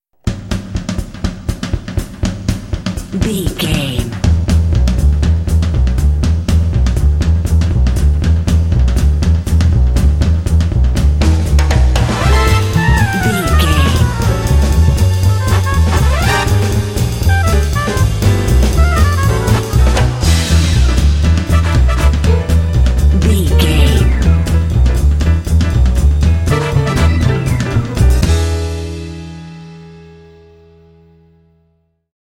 Uplifting
Aeolian/Minor
Fast
energetic
lively
cheerful/happy
drums
double bass
brass
piano
big band
jazz